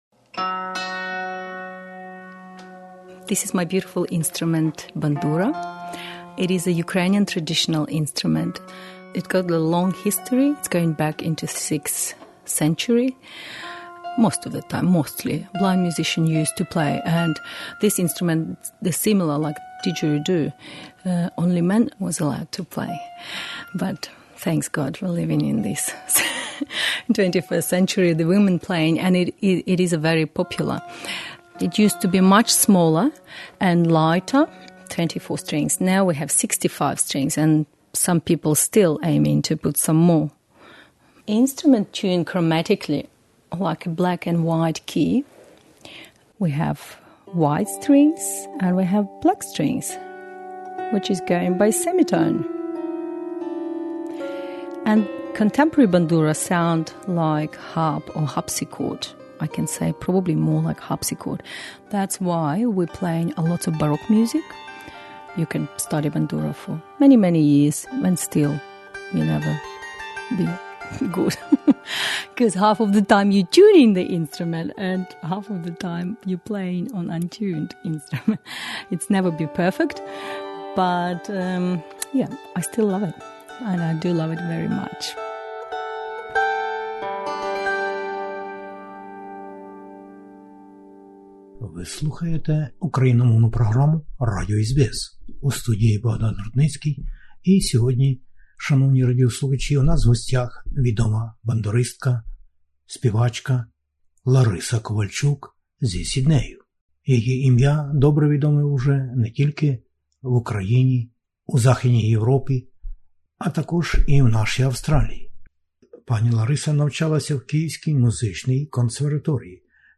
Отож, тепер відома мисткиня у нас в гостях знову...